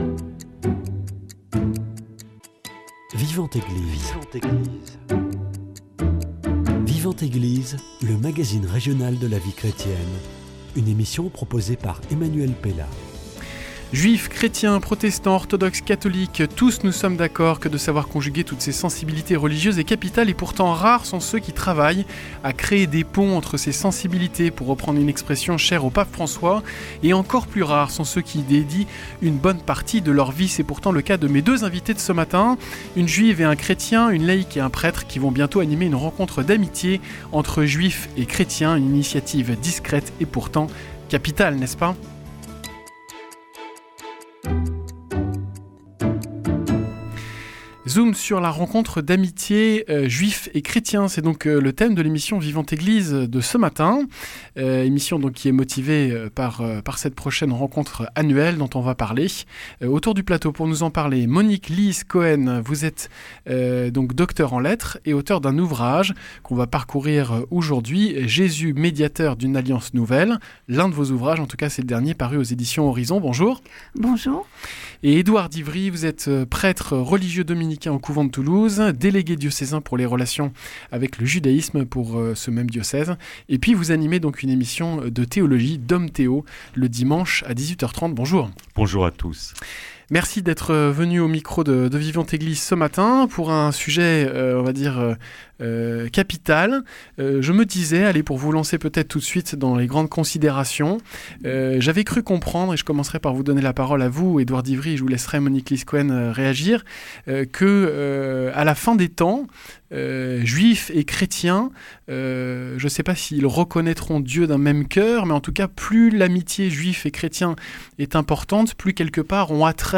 Ils sont nos "frères aînés" mais en dépit de tous les efforts de dialogue et de rapprochements, bien des à priori demeurent. Aujourd’hui, je vous propose un dialogue entre une laïc juive et un prêtre catholique sur la figure de Jésus.